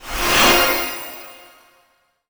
magic_shine_light_spell_04.wav